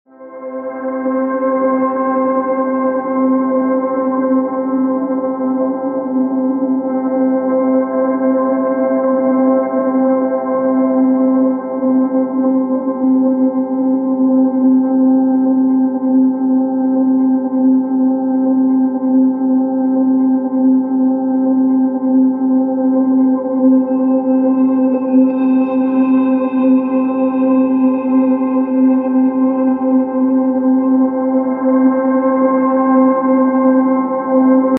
This transmission carries crystalline harmonics, channeled from the higher realms to awaken your soul’s memory.
🎧 Full transmission now available on YouTube Ascension Chamber | Healing Frequencies from the Higher Realms 🕊 You are not ascending- you are remembering.